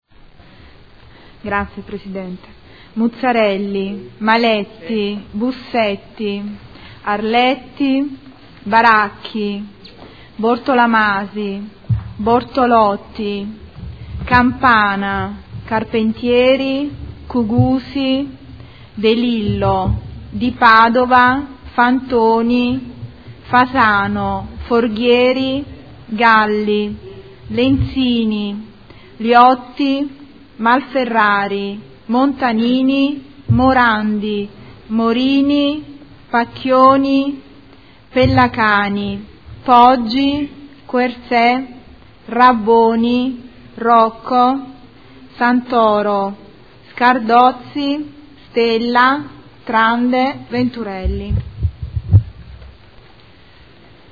Segretario Generale — Sito Audio Consiglio Comunale
Seduta del 26/03/2015. Appello